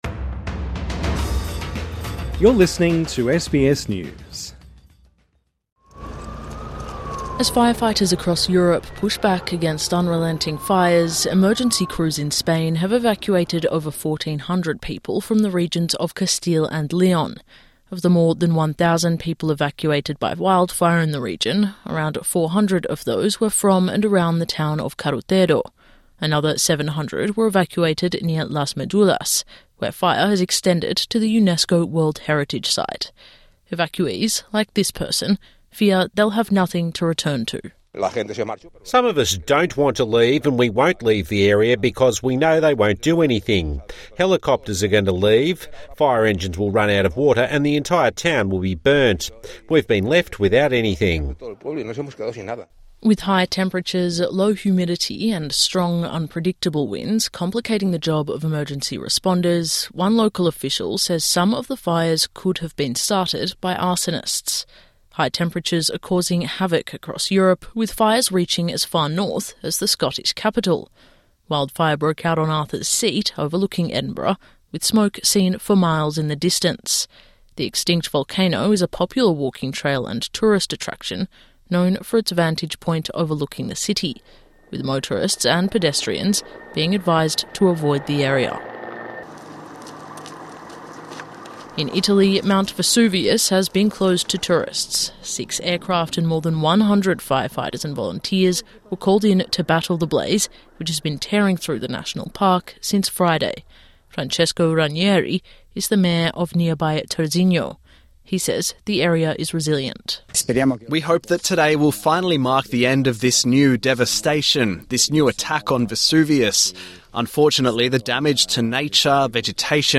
SBS News In Depth